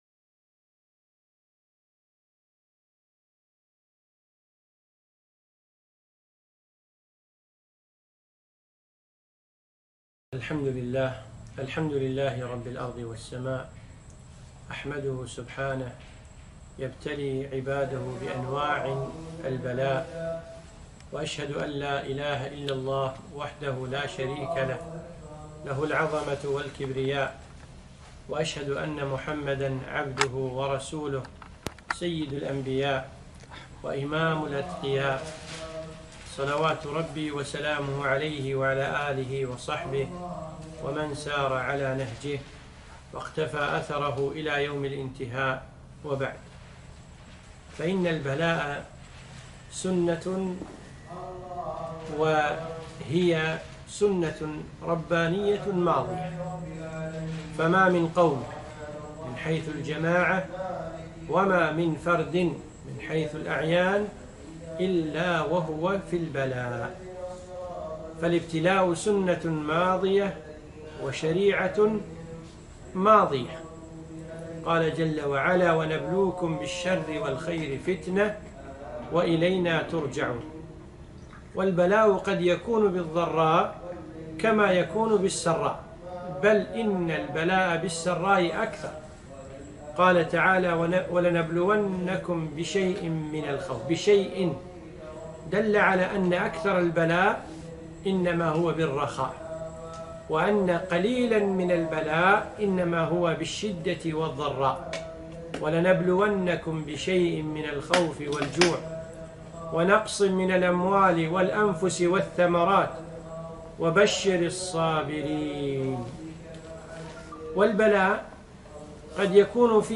محاضرة - أسباب رفع البلاء